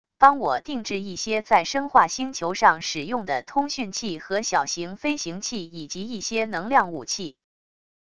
帮我定制一些在生化星球上使用的通讯器和小型飞行器以及一些能量武器wav音频